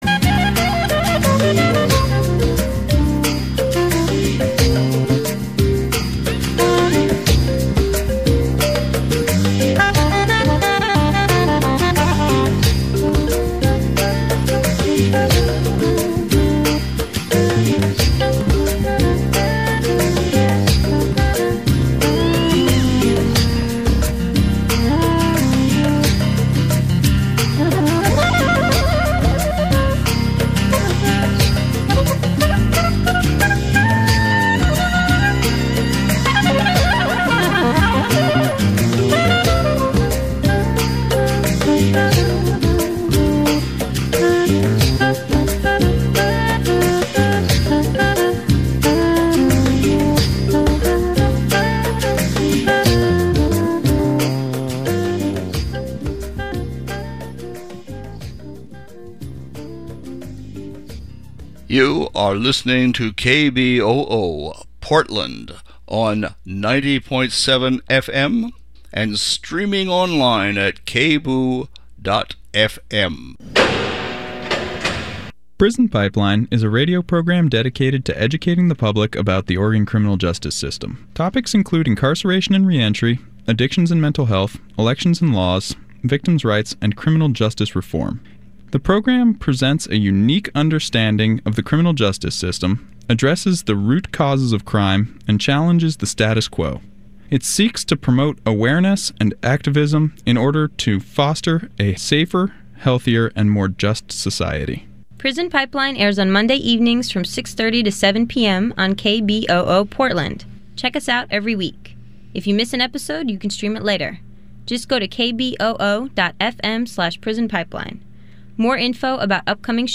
Views, Reviews, and Interviews